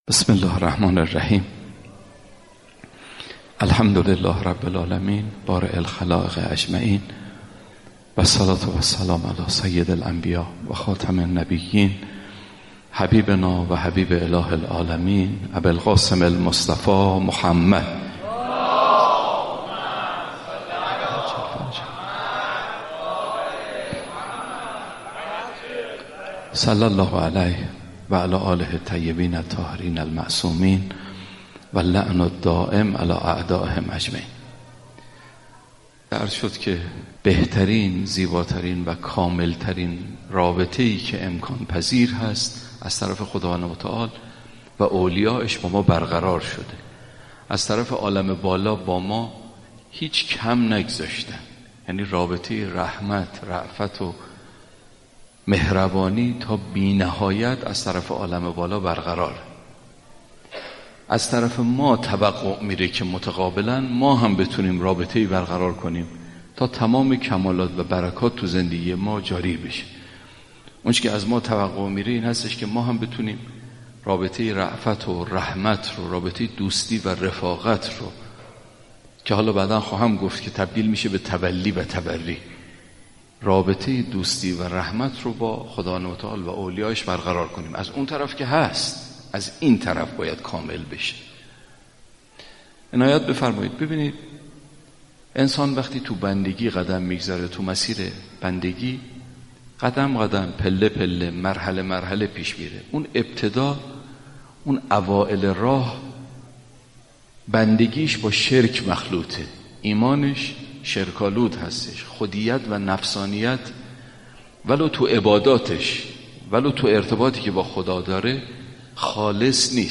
متن سخنرانی